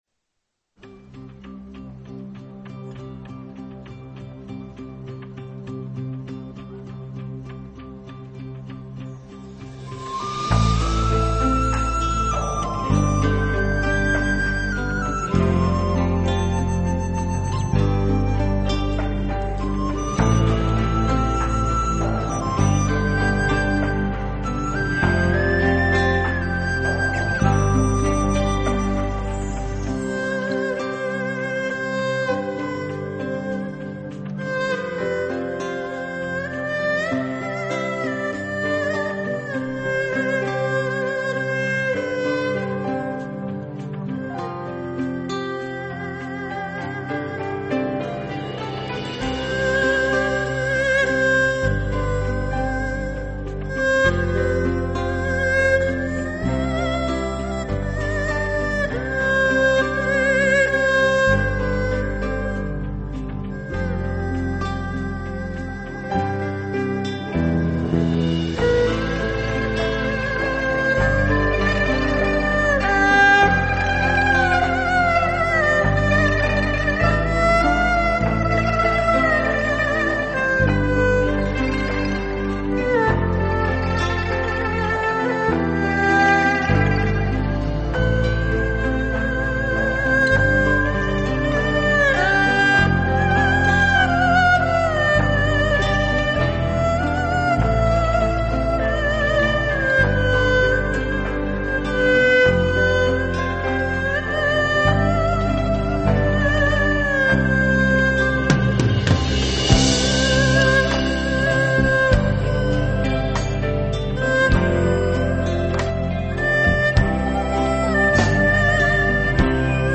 专辑语言：纯音乐1CD
中国民乐与欧美流行歌曲的结合，赋予了作品全新的生命，给人耳目一新的感觉。